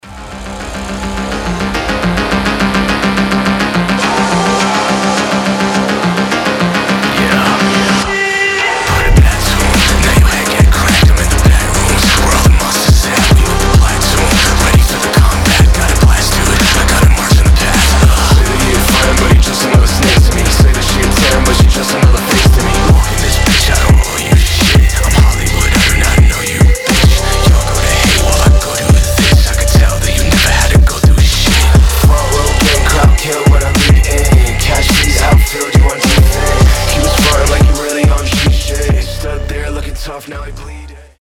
Trap
phonk
Эпичный фонк